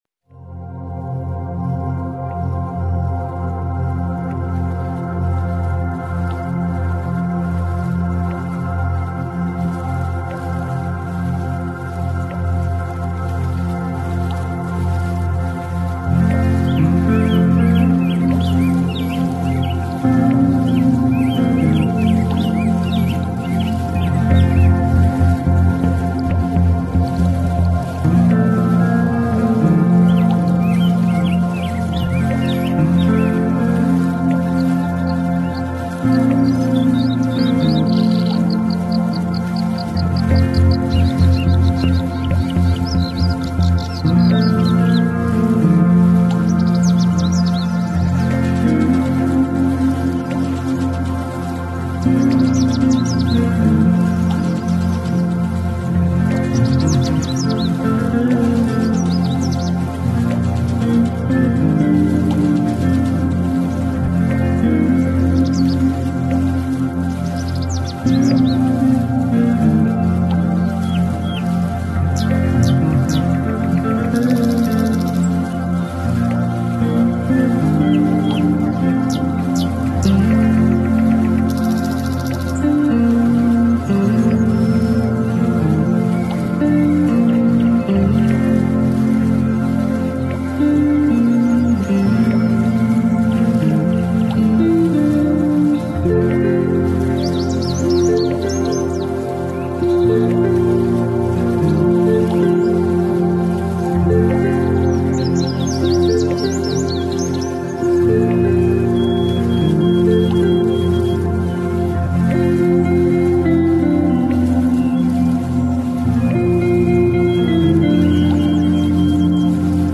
🦁✨ Meditación 8D de Conexión Espiritual para el Portal 8/8 Activa tu energía en este poderoso día con una experiencia inmersiva que combina sonido 8D, frecuencia 852 Hz y un fractal del Cubo de Metatrón para elevar tu vibración y conectar con tu esencia más pura.
Esta meditación guiada te ayudará a: 💫 Elevar tu vibración y claridad interior 💫 Activar tu intuición y conexión espiritual 💫 Intencionar manifestaciones en sincronía con el Portal 8/8 🎧 Ponte tus audífonos y sumérgete en una experiencia envolvente que une geometría sagrada, frecuencias de alta vibración y la magia del sonido 8D.